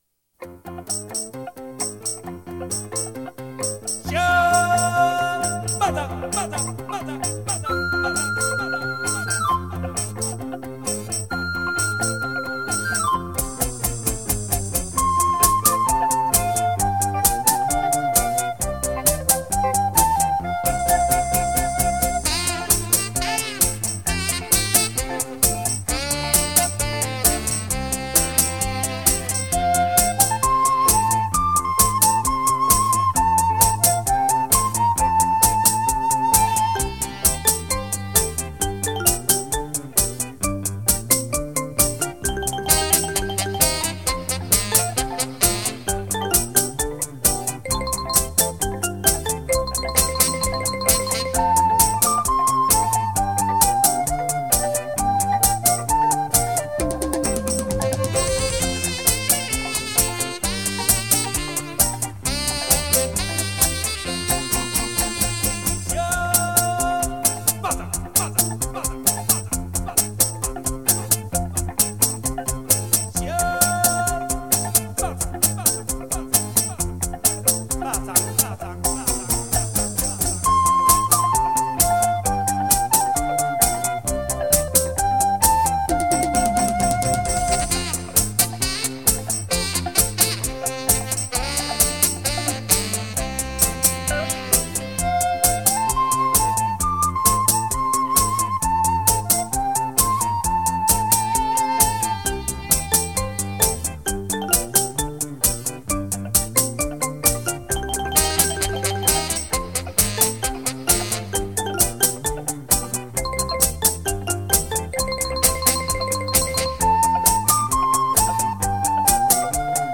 扭扭舞